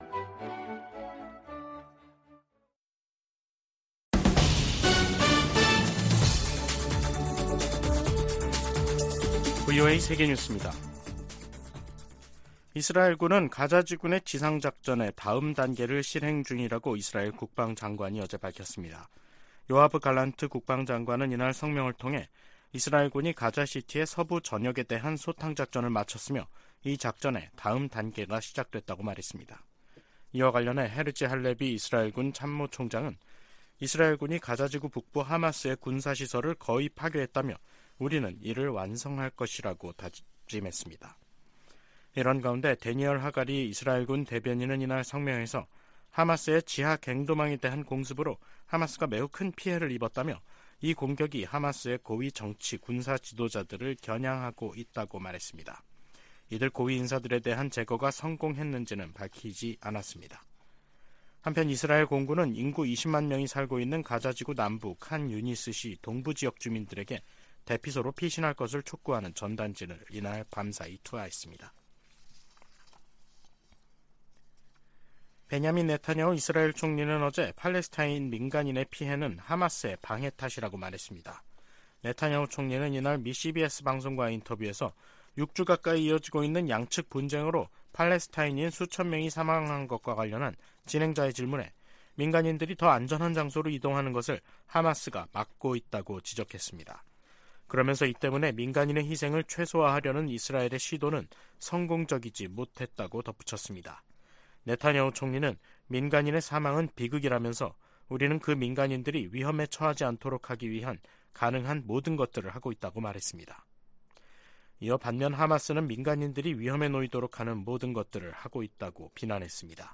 VOA 한국어 간판 뉴스 프로그램 '뉴스 투데이', 2023년 11월 17일 3부 방송입니다. 조 바이든 미국 대통령과 기시다 후미오 일본 총리가 타이완해협, 한반도, 동중국해 등에서의 평화와 안정이 중요하다는 점을 재확인했습니다. 미국은 동맹국의 핵무기 추구를 단호히 반대해야 한다고 국무부의 안보 관련 자문위원회가 주장했습니다. 미중 정상이 양국 간 갈등을 완화하는 데 동의함으로써 한반도 안보 상황에도 긍정적으로 작용할 것이라는 관측이 나오고 있습니다.